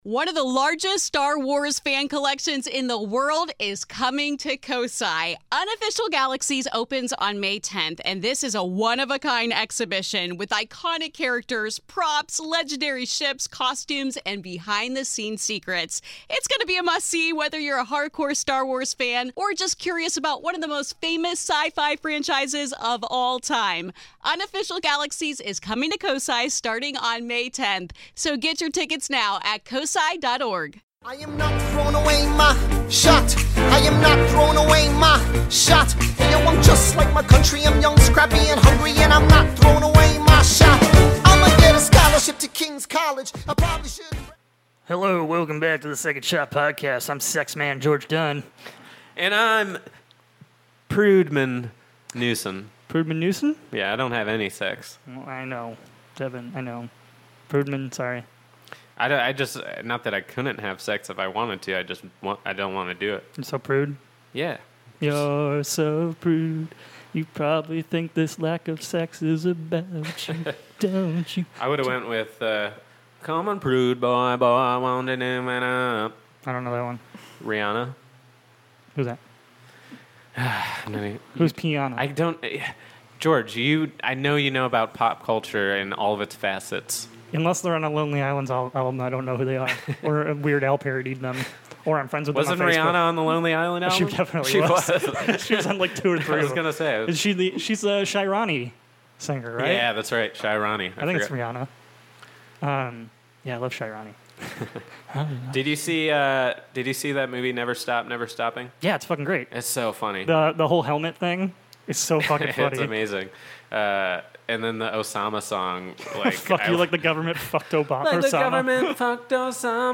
We get REAL drunk on this episode (Wine Guy make a strong appearance!). It's a fun episode full of weird tangents.